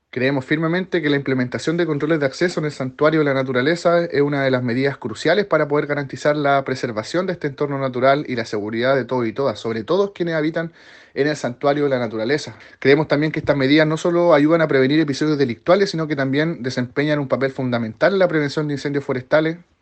En esa misma línea, el concejal Matías Rifo, presidente de la comisión de Medio Ambiente y Turismo pidió reforzar las medidas de seguridad a fin de evitar la comisión de delitos en esa zona.